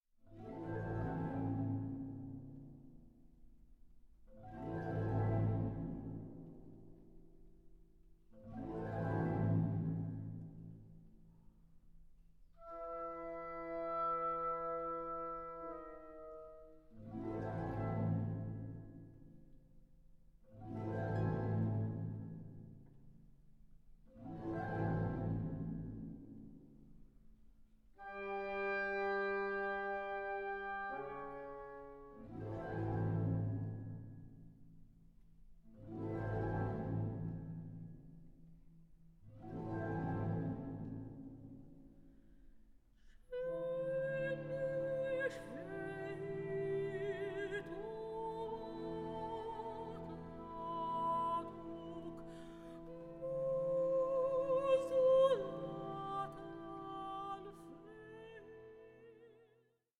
44.1/16 Stereo  10,99 Select
Opera in one act